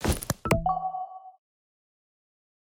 pda_draw.ogg